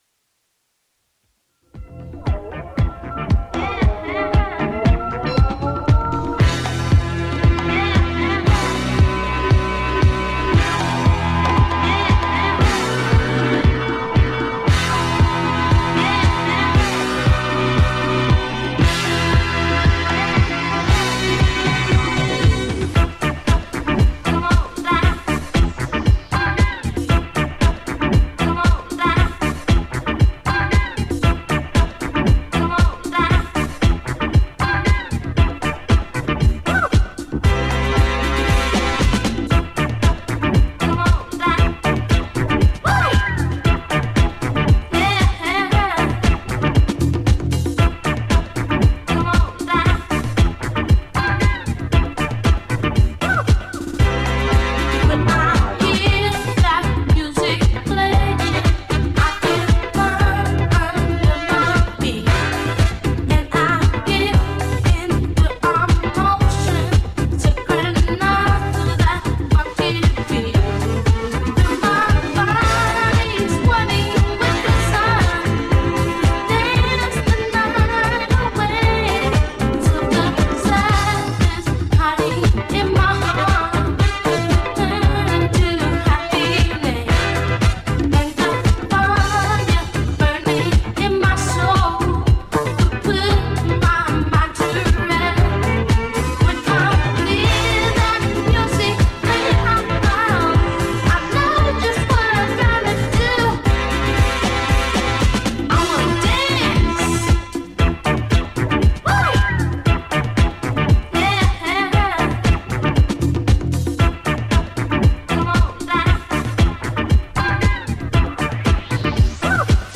ジャンル(スタイル) DISCO / FUNK / RE-EDIT